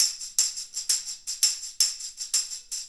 Tambourine 04.wav